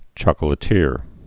(chôkə-lĭ-tîr, chôklĭ-tîr, chŏk-)